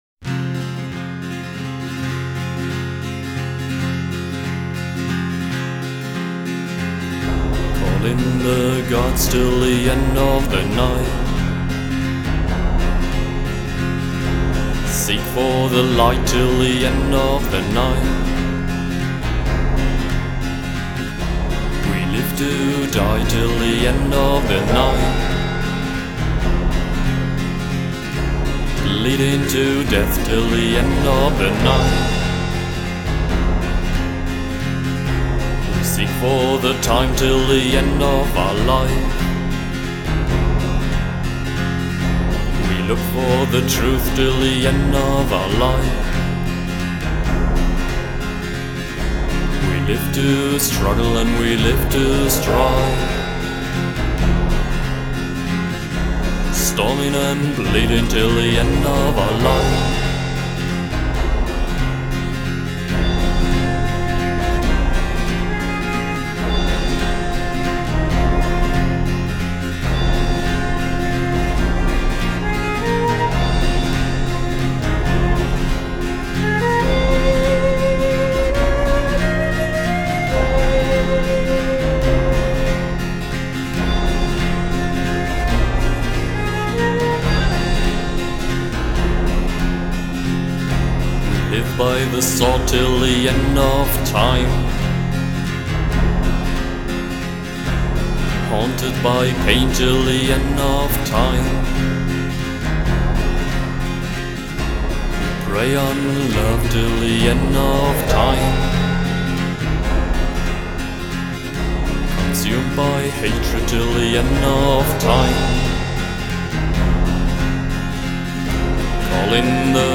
Стиль: Dark Folk